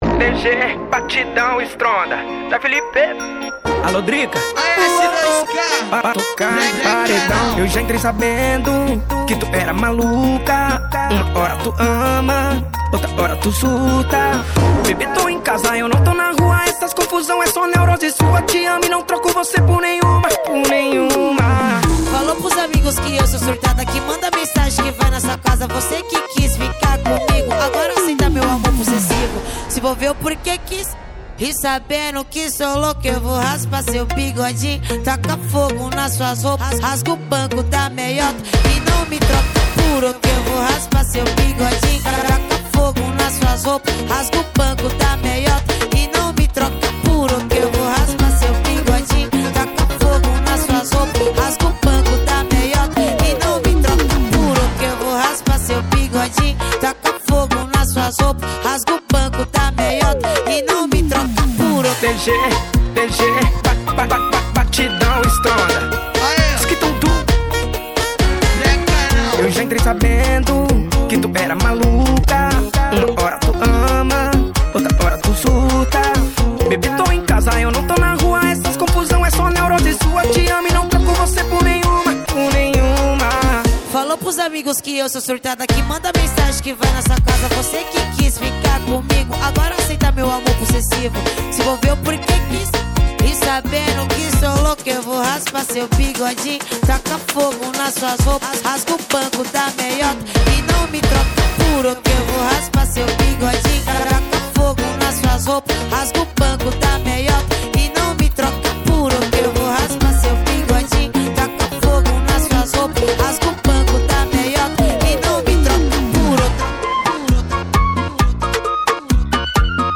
Gênero: MPB